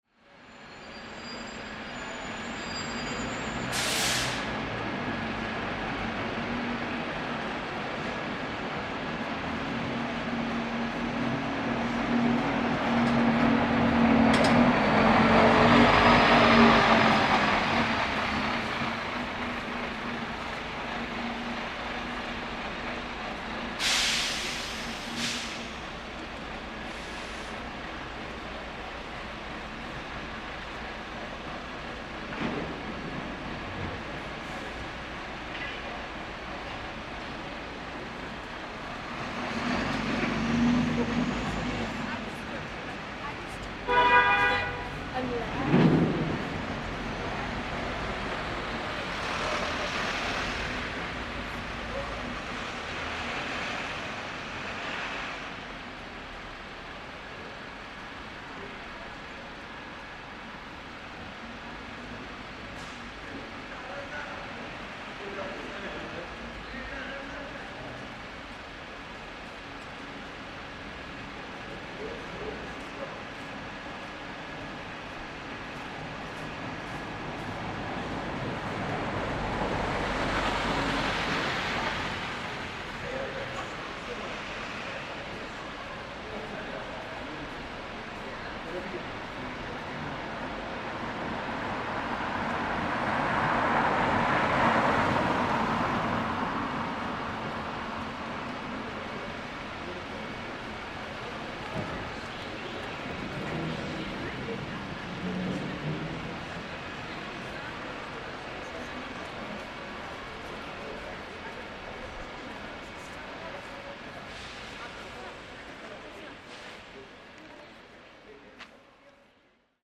Recording in a common space of shoppers, local buses driving in peak times of traffic, fewer people in the area, and space becomes much quieter. Beginning of Lockdown 2 in Belfast.